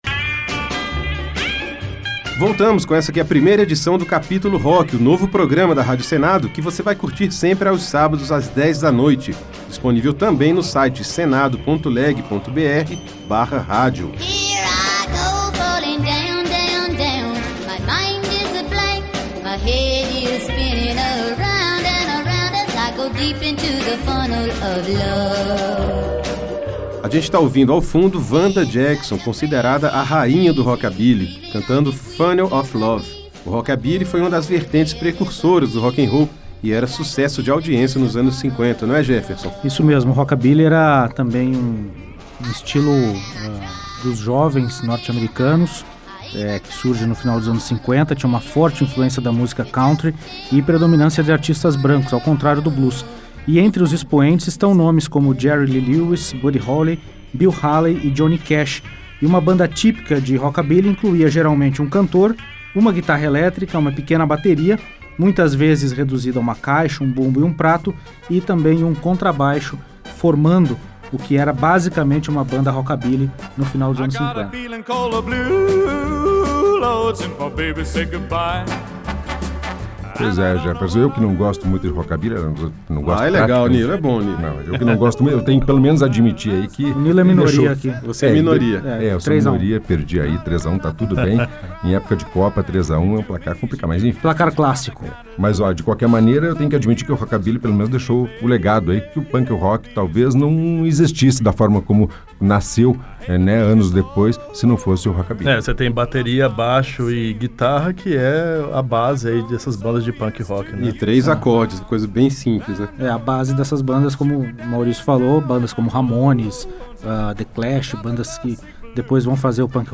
Rock dos Anos 50